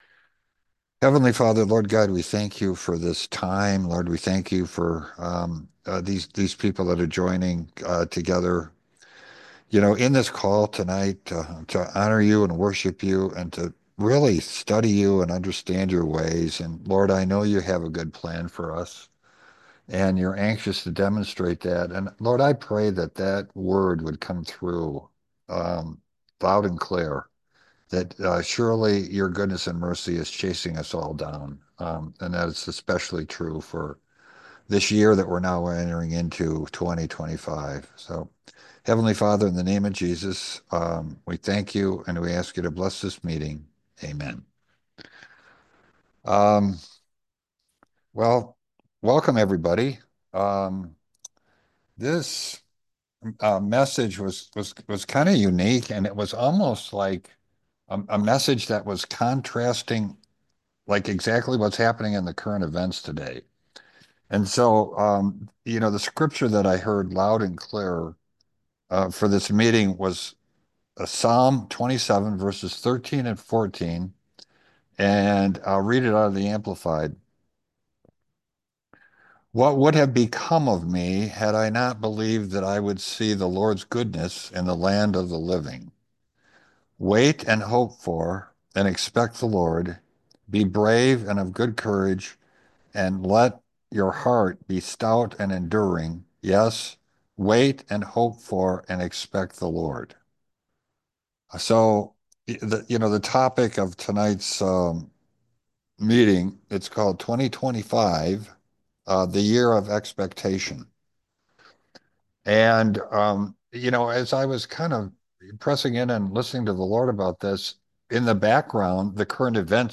Sermons | Spirit Of God Ministries WorldWide